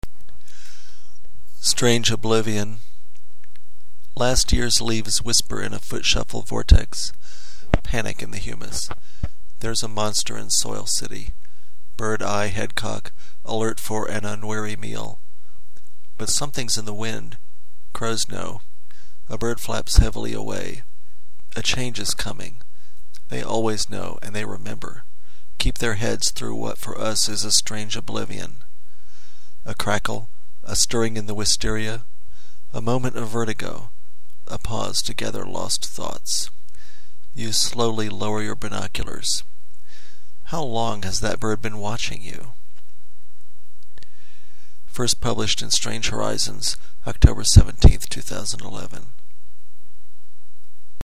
2012 Halloween Poetry Reading